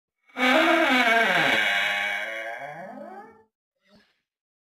Door Opens
Category: Sound FX   Right: Personal
Tags: radio drama